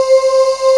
BREATH VOX.wav